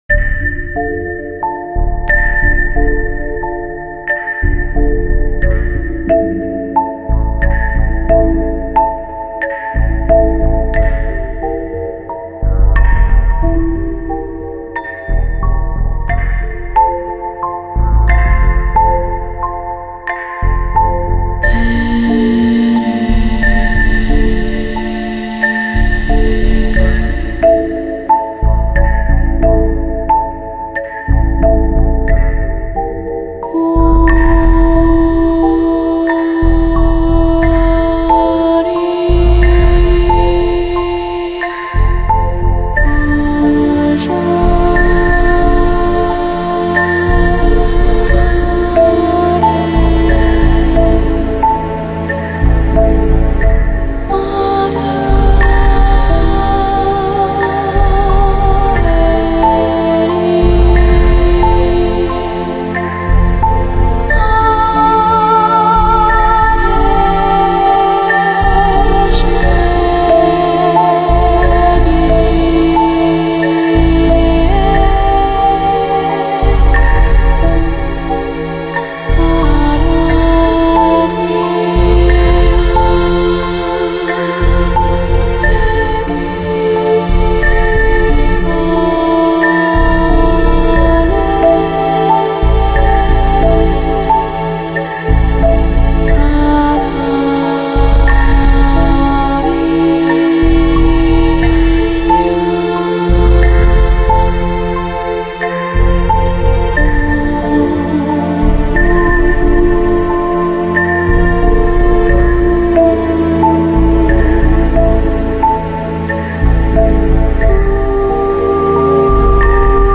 Chill Out, Lounge, Downtempo, Balearic